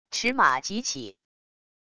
驰马急起wav音频